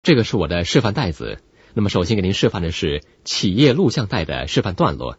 Professioneller chinesischer Sprecher für TV/Rundfunk/Industrie.
chinesischer Sprecher
Sprechprobe: Werbung (Muttersprache):